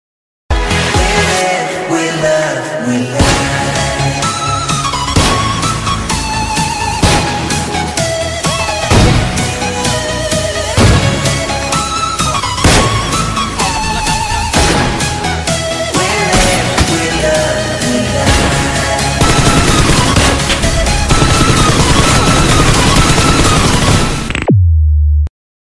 SFX音效